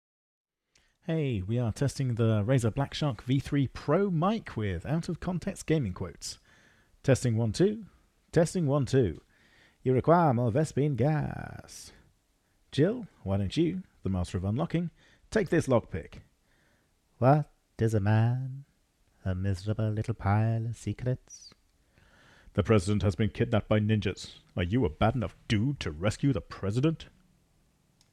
Chế độ “broadcast” mang lại chất lượng âm thanh rất ổn.
Broadcast EQ: